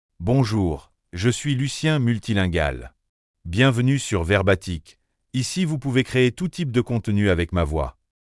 Lucien MultilingualMale French AI voice
Lucien Multilingual is a male AI voice for French (France).
Voice sample
Listen to Lucien Multilingual's male French voice.
Male